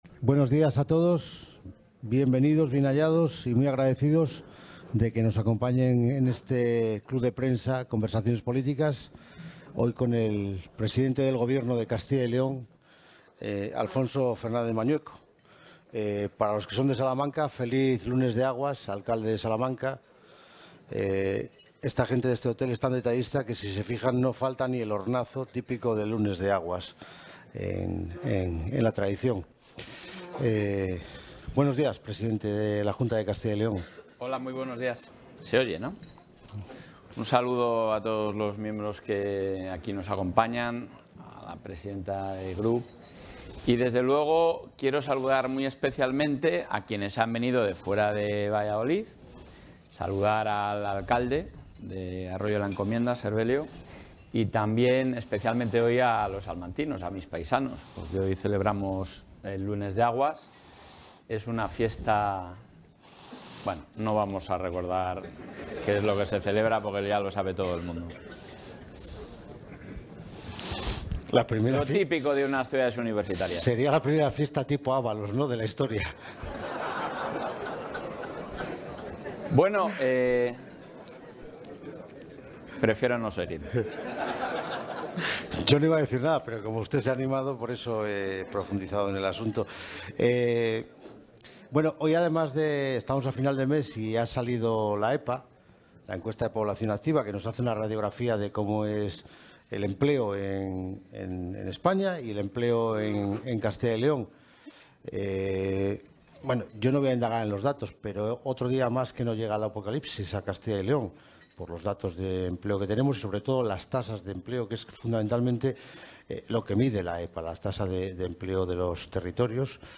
Intervención del presidente de la Junta.
El presidente de la Junta de Castilla y León, Alfonso Fernández Mañueco, ha participado hoy en Valladolid en el encuentro 'Conversaciones Políticas', organizado por El Club de Prensa de El Mundo, Diario de Castilla y León, en el que se han abordado diferentes temas de actualidad.